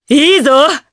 Morrah-Vox_Happy4_jp.wav